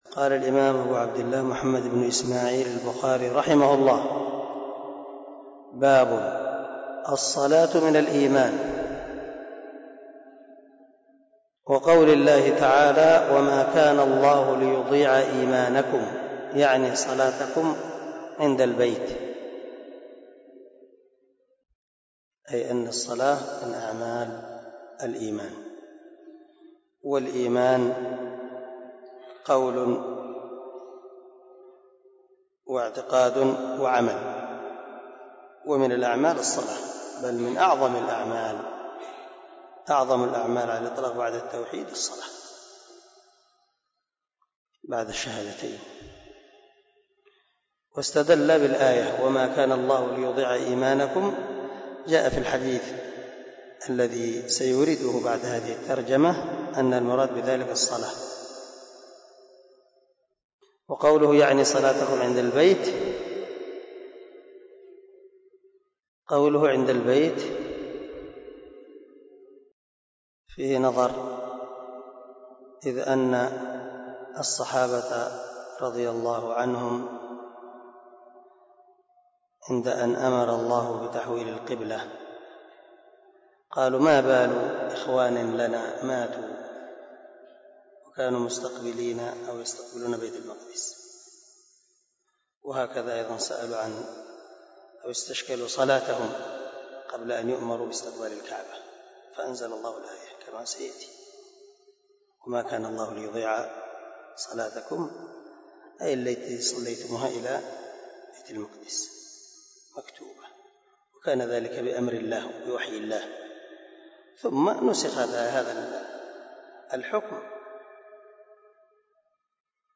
039الدرس 29 من شرح كتاب الإيمان حديث رقم ( 40 ) من صحيح البخاري